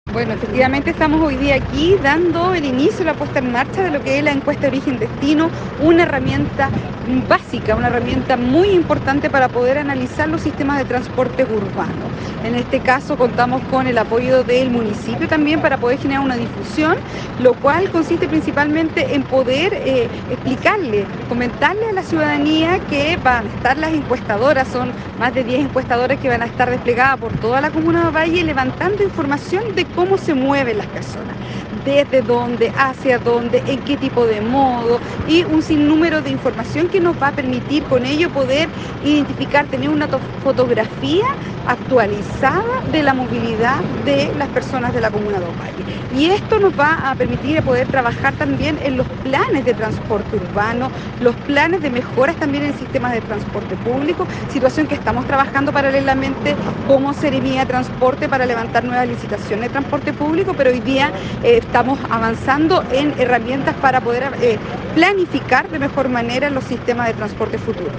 Respecto de la importancia de esta encuesta, la SEREMI de Transportes Alejandra Maureira indicó que
SEREMI-ALEJANDRA-MAUREIRA.mp3